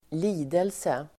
Uttal: [²l'i:delse]